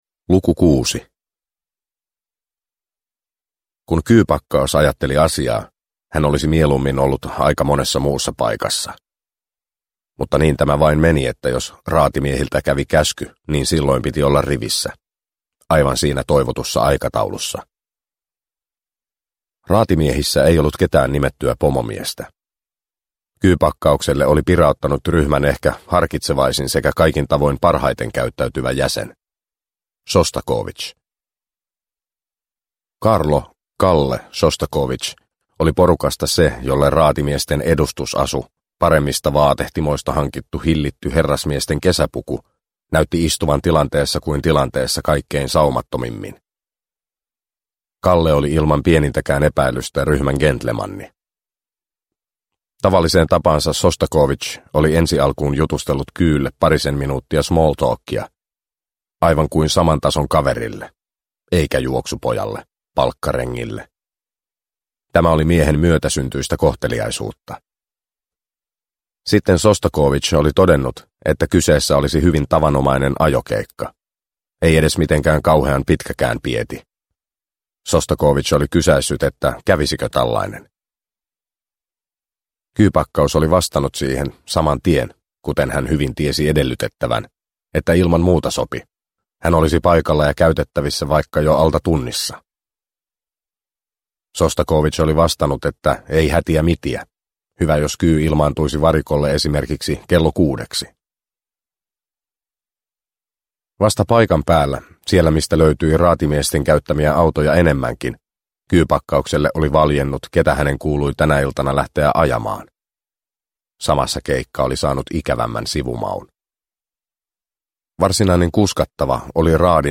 Sheriffi – Ljudbok – Laddas ner
Uppläsare: Ville Tiihonen